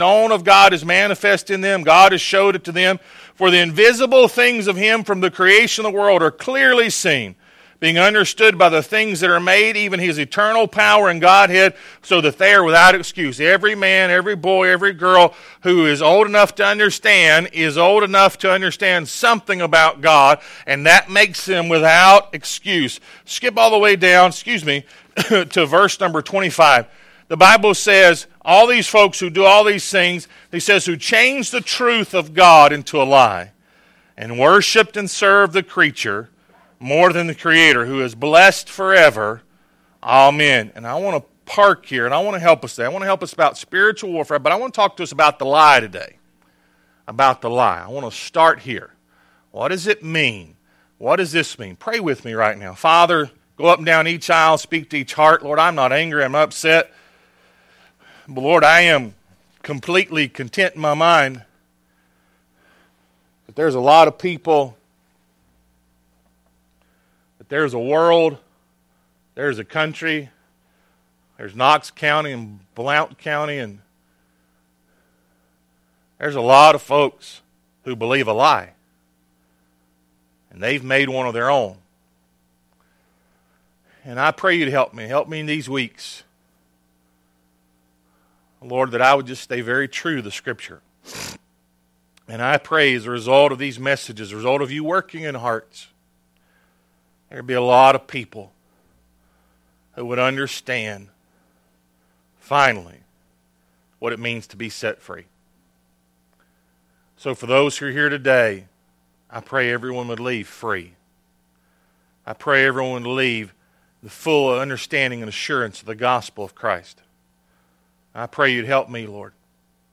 Romans 1:16-32 Service Type: Sunday AM Spiritual Warfare